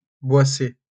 Boissets (French pronunciation: [bwasɛ]